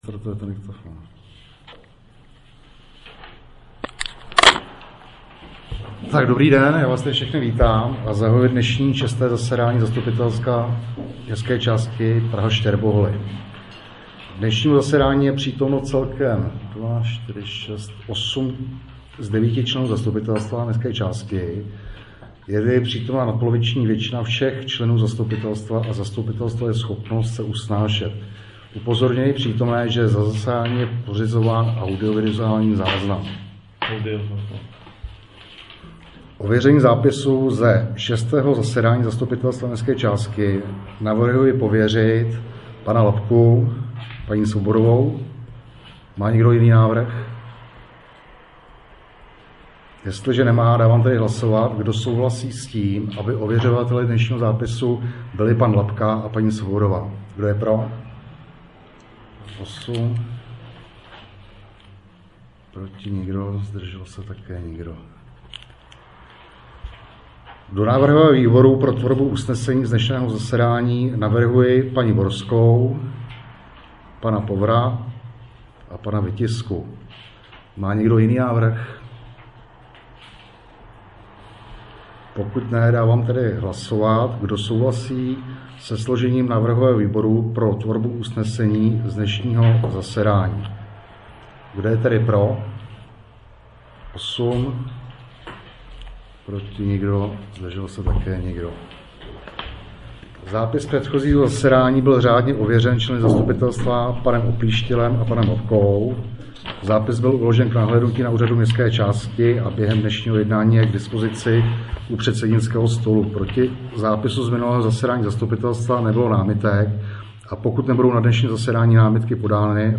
6. ZASEDÁNÍ ZASTUPITELSTVA MČ PRAHA ŠTĚRBOHOLY zvukový záznam ze ZMČ